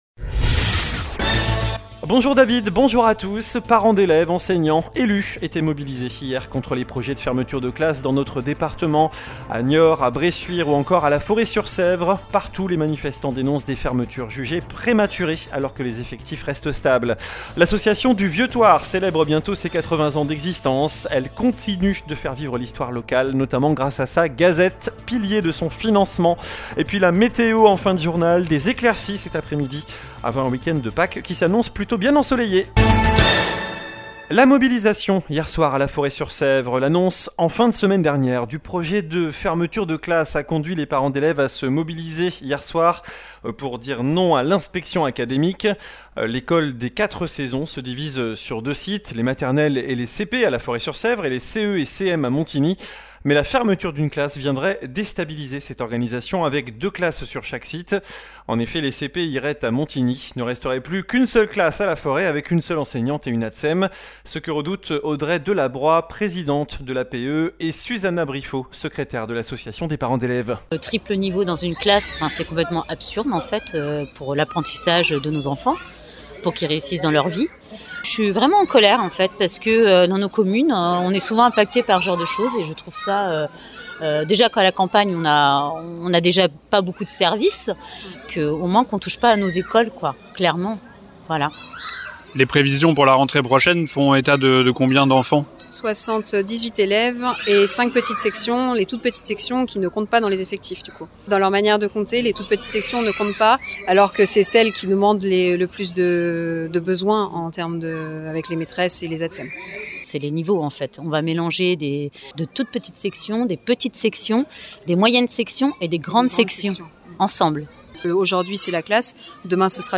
Journal du mercredi 1er avril (midi)